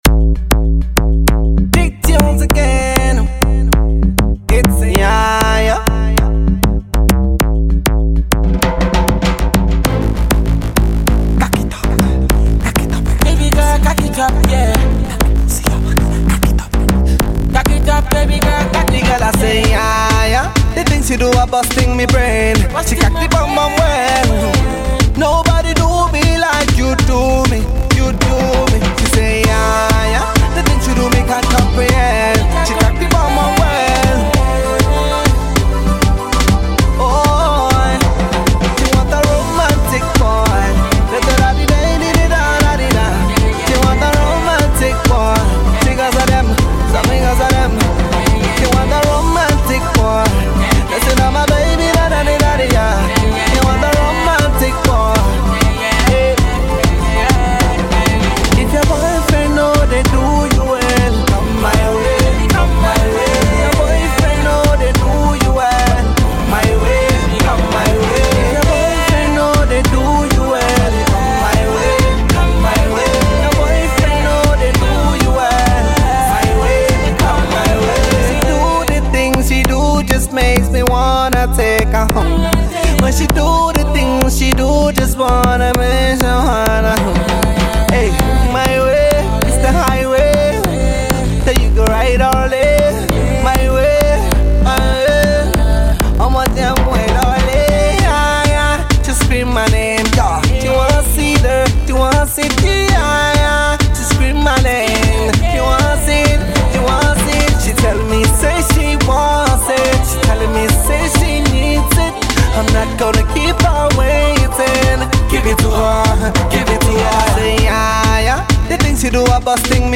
single
a mix and match of dance hall songs